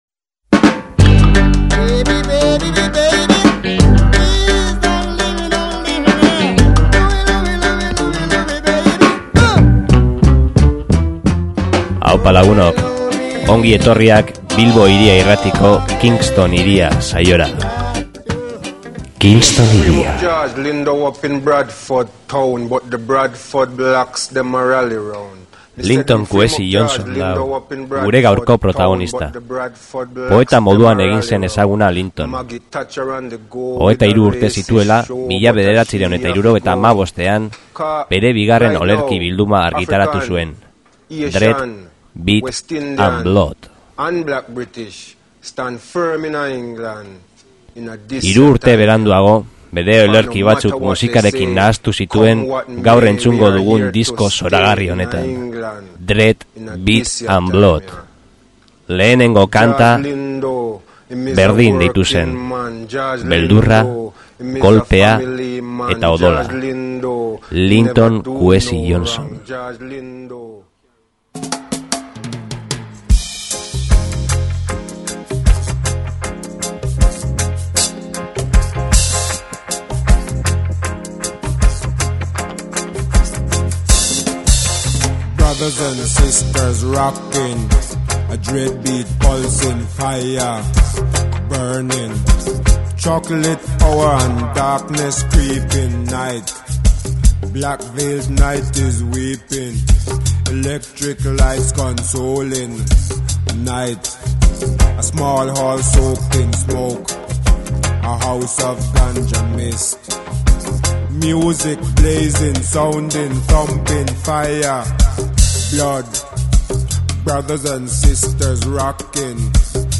Hau da, olerki errezitatuak dub musikaren gainetik.